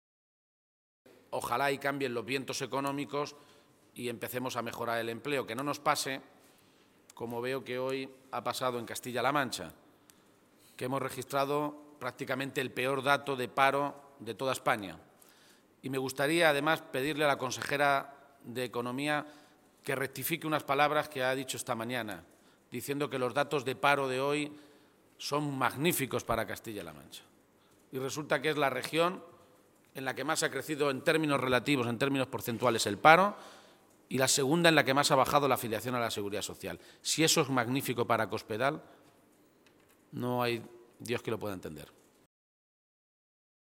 García-Page se pronunciaba de esta manera, en Toledo, a preguntas de los medios de comunicación, e insistía en que “mientras que en el resto de España el paro baja, en Castilla-La Mancha el paro no solo sube, sino que en términos porcentuales es la comunidad autónoma donde más crece el desempleo”.
Cortes de audio de la rueda de prensa